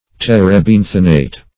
Search Result for " terebinthinate" : The Collaborative International Dictionary of English v.0.48: Terebinthinate \Ter`e*bin"thi*nate\, a. Impregnating with the qualities of turpentine; terbinthine.
terebinthinate.mp3